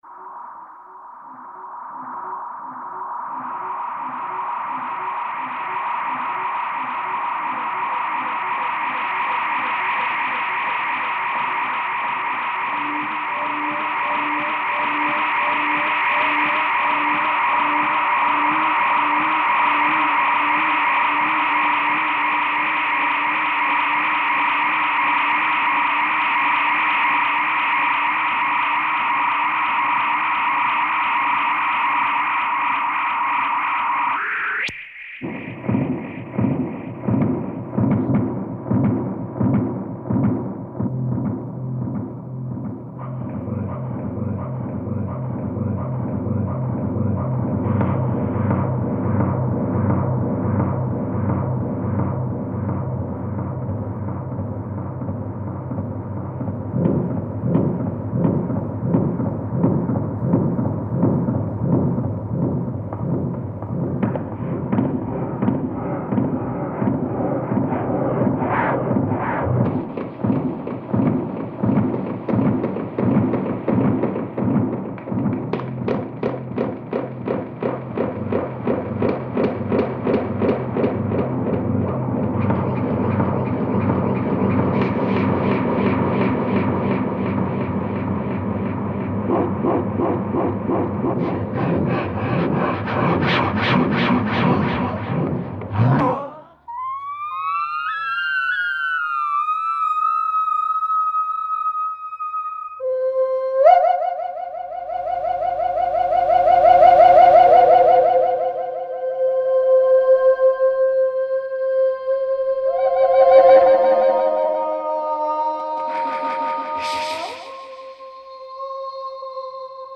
Obra electroacústica.